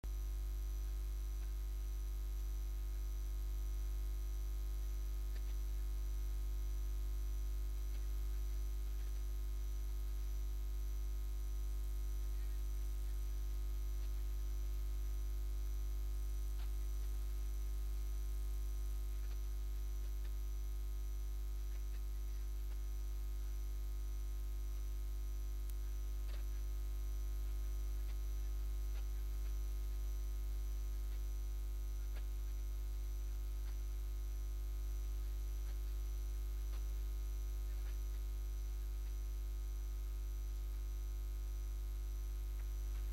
Durante el acto de entrega de los Premios Pablo Iglesias en la Caseta del PSOE en la Feria de Albacete
Cortes de audio de la rueda de prensa